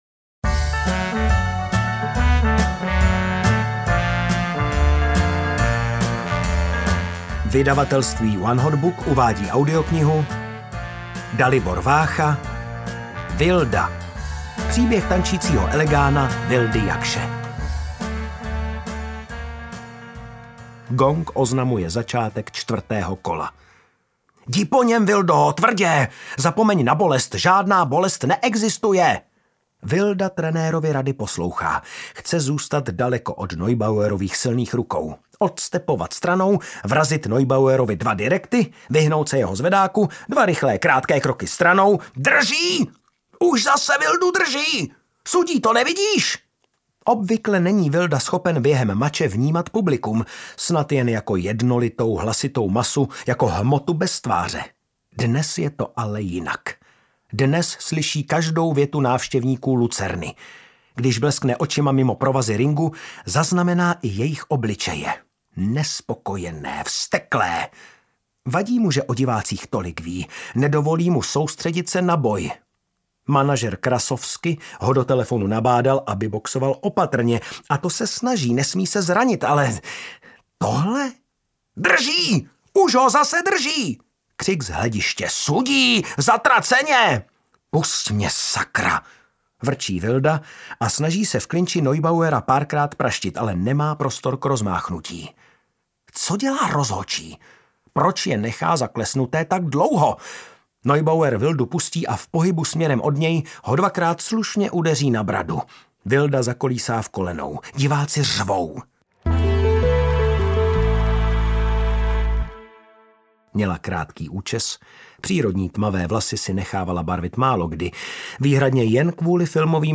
Ukázka z knihy
vilda-audiokniha